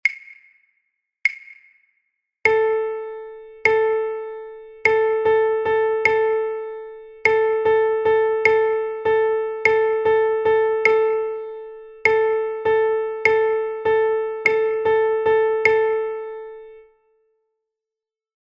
Now it is time to work with duplets. Look at the image and listen to the sound, which only shows a metronome rhythmic pattern to assimilate the special value group of a duplet in a binary meter with ternary subdivision (6/8).